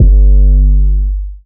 DDWV 808 5.wav